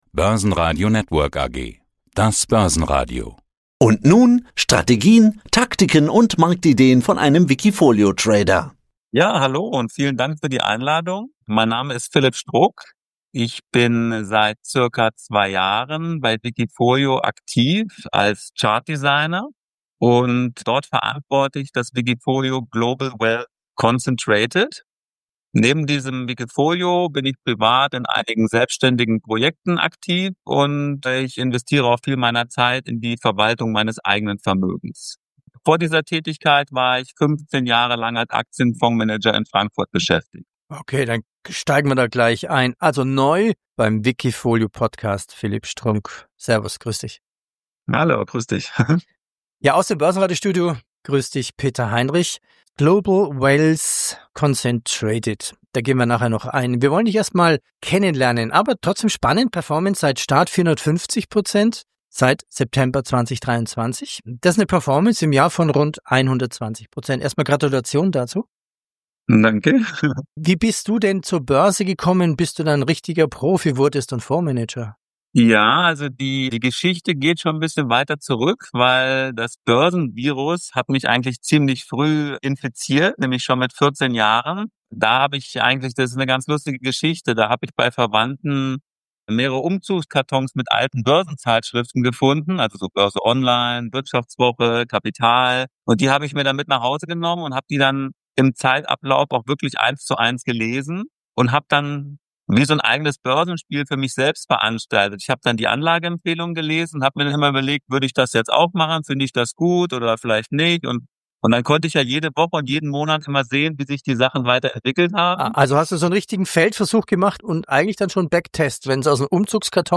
Die besten wikifolio-Trader im Börsenradio Interview Podcast